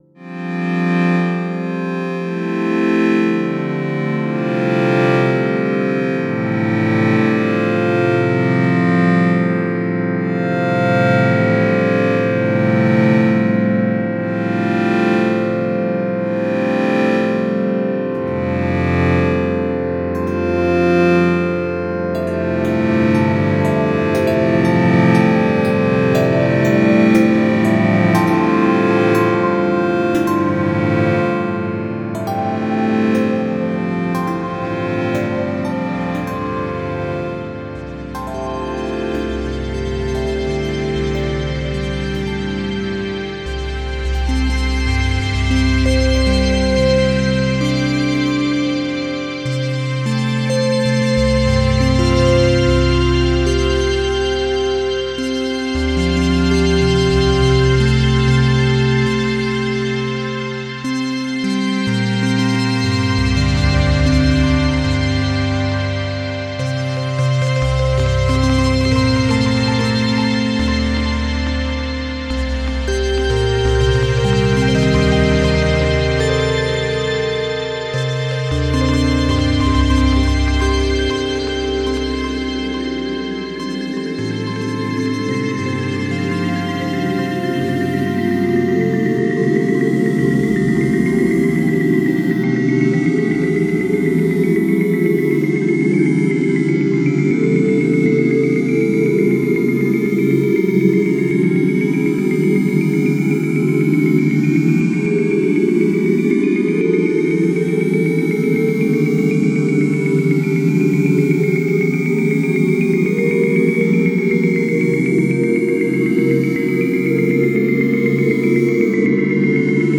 This time Cycles & Spots created a polyphonic sequencer instrument for NI Reaktor to trigger either the inbuilt synthesizer or external ones. It has several sequencing modes from traditional to Random to LFO but even the traditional one has numerous order options like backwards, inside out, zigzag and more.
Speaking of the latter, it’s an analog modeled synth which let’s you mix 4 oscillators where the sine is FM-able and the pulse with width modulation option.